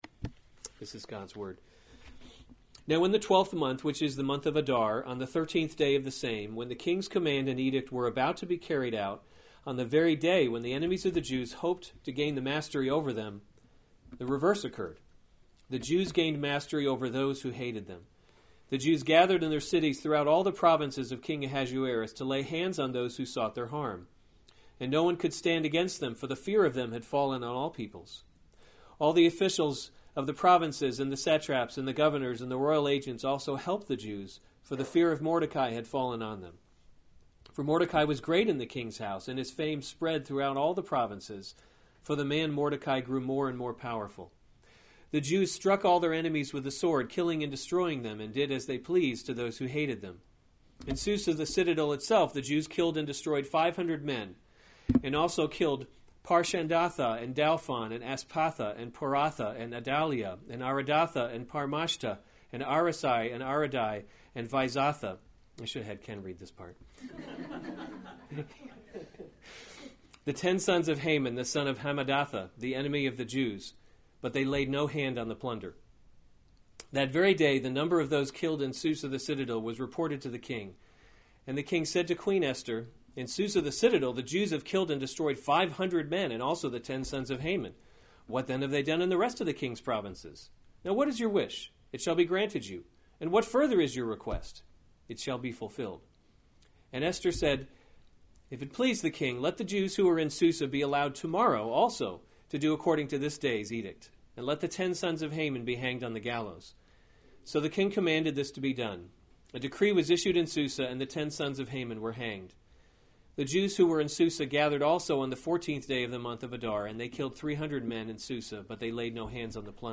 January 14, 2017 Esther: God’s Invisible Hand series Weekly Sunday Service Save/Download this sermon Esther 9:1-19 Other sermons from Esther The Jews Destroy Their Enemies 9:1 Now in the twelfth month, […]